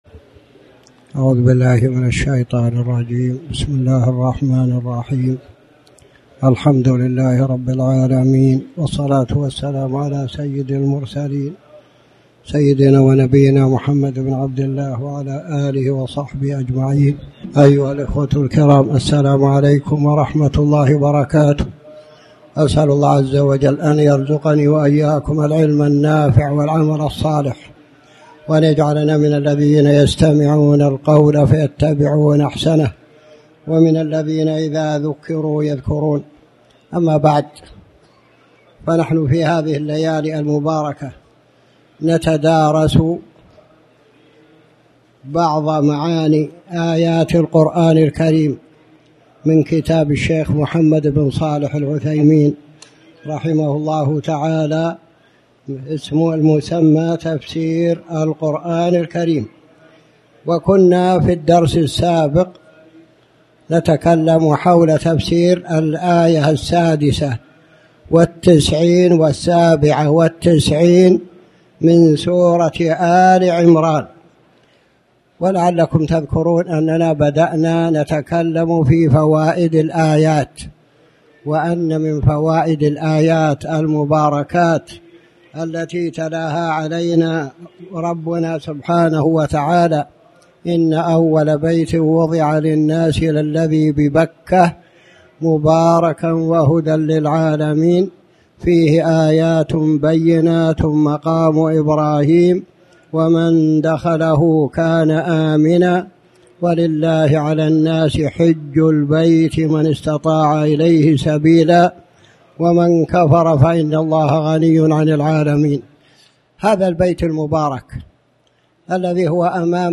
تاريخ النشر ١٤ ربيع الأول ١٤٣٩ هـ المكان: المسجد الحرام الشيخ